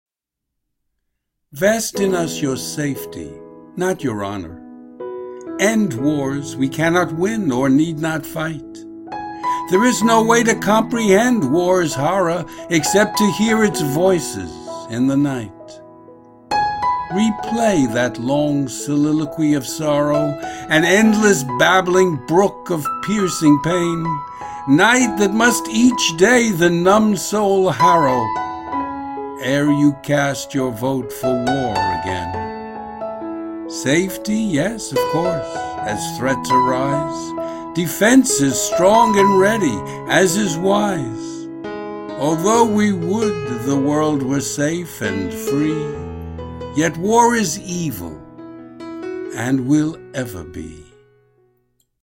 Audio and Video Music: